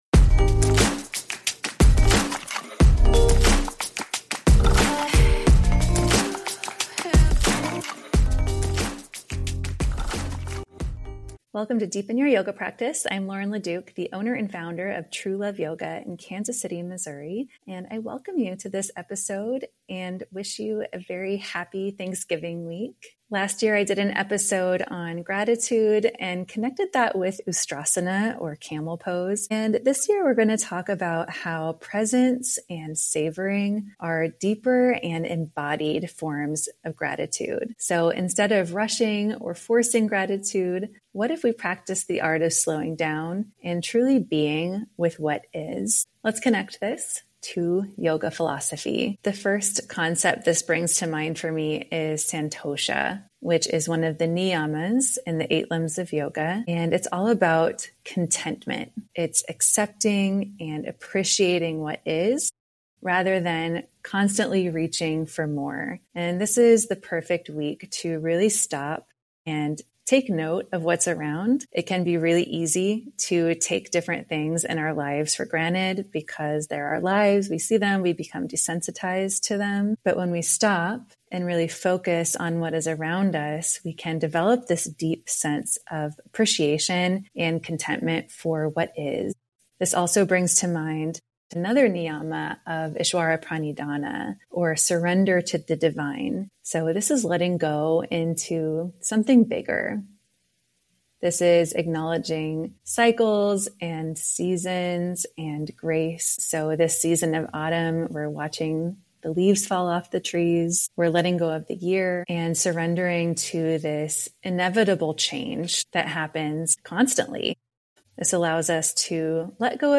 In this solo episode of Deepen Your Yoga Practice, you’ll explore yogic teachings like: